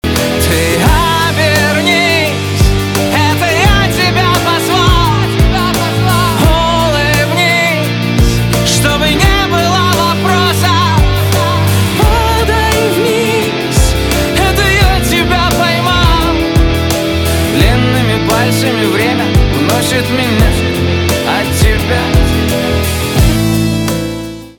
русский рок
грустные , гитара , барабаны